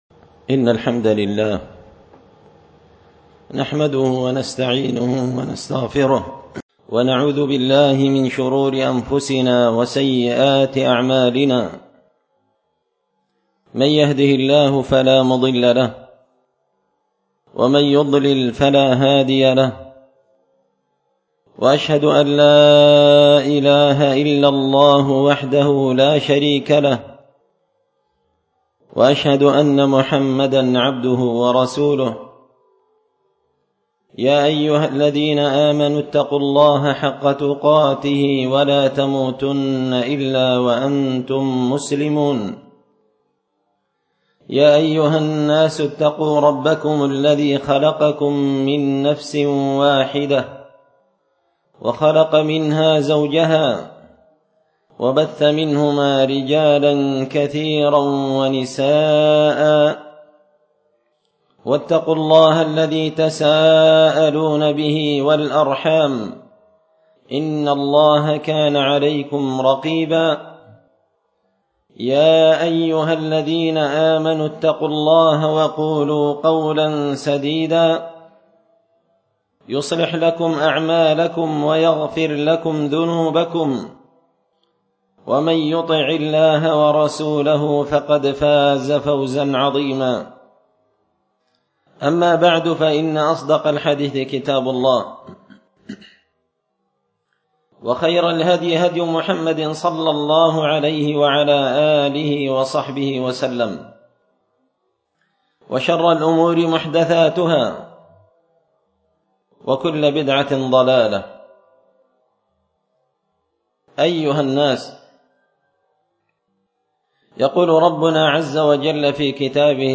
خطبة جمعة بعنوان – محبة النبي صلى الله عليه وسلم بين الحقيقة والادعاء
دار الحديث بمسجد الفرقان ـ قشن ـ المهرة ـ اليمن